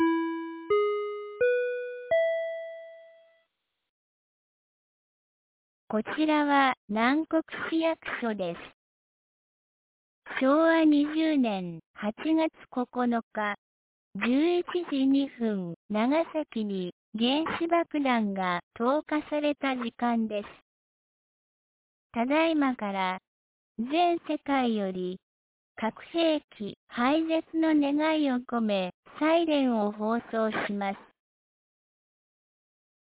2025年08月09日 11時01分に、南国市より放送がありました。